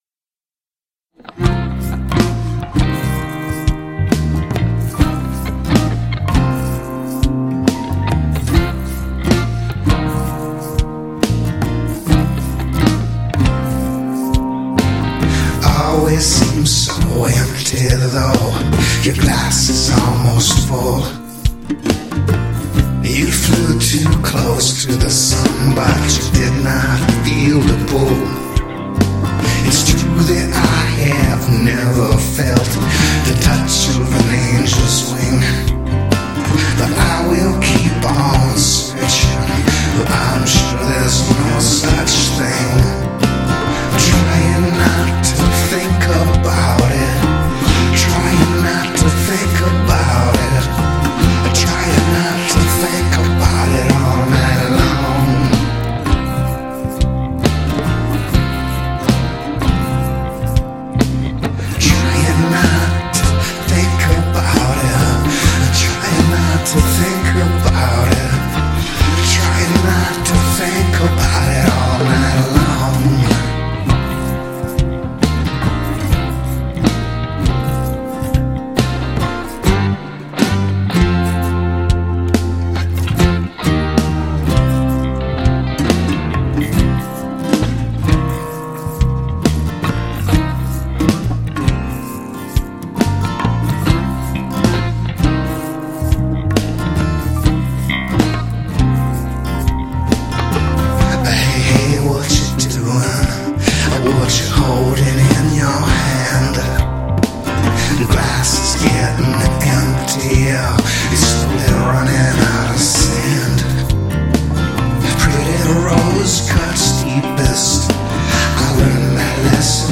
Its not finished, but I am such a crummy singer that there is not much point in taking things too far. I'm in and out of the pocket more than a crackheads bic, but I was just figuring how these DAW thingies work and hadn't gotten to...